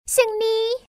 Win_Voice.mp3